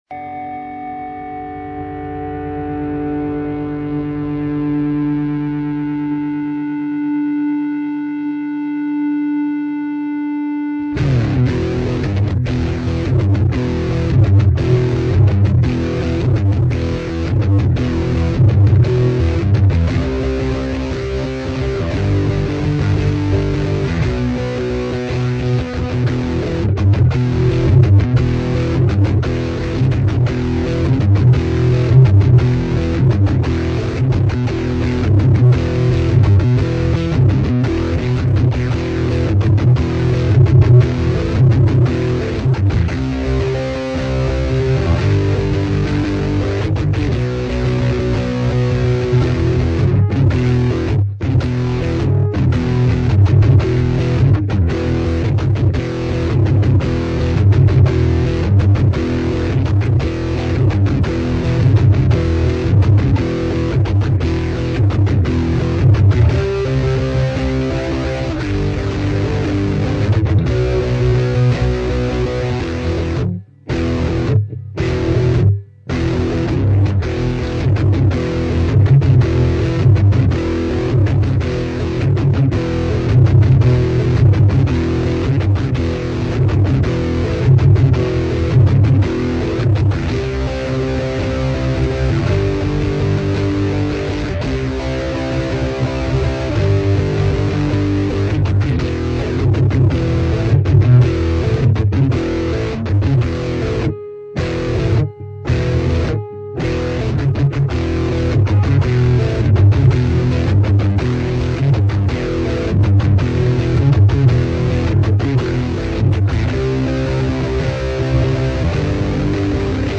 Below are just a few riffs that I recorded so I won't forget.
- Strat through the Roland, and flange added via Audition.